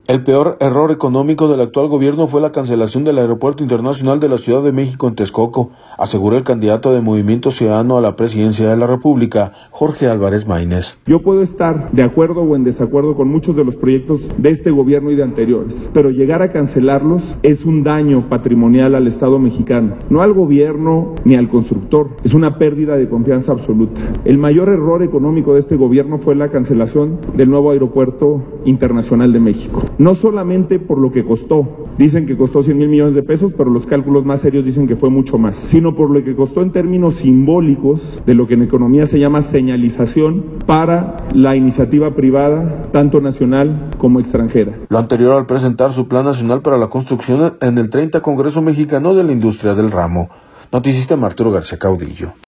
Lo anterior al presentar su Plan Nacional para la Construcción en el 30 Congreso Mexicano de la Industria del ramo.